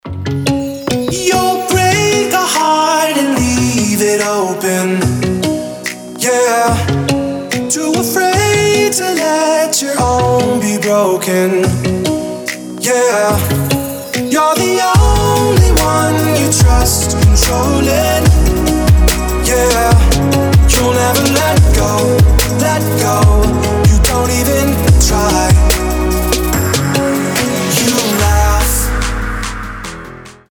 • Качество: 320, Stereo
мужской вокал
красивые
dance
Electronic
EDM
спокойные